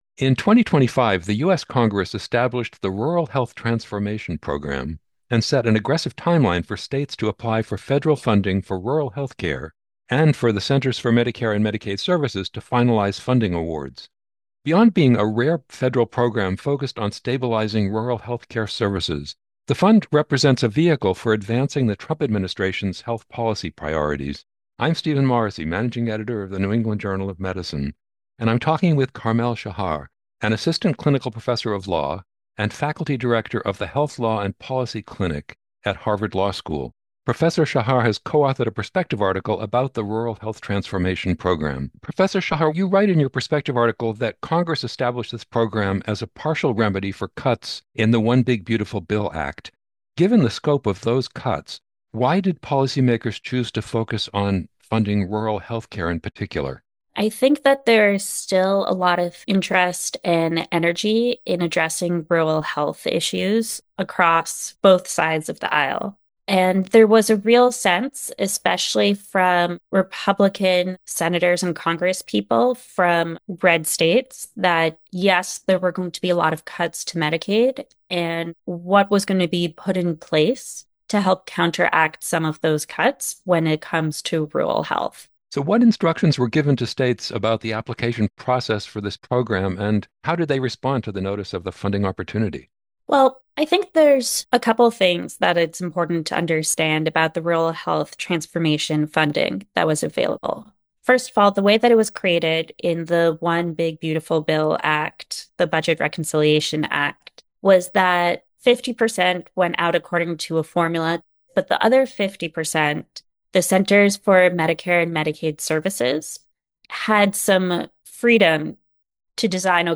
NEJM Interviews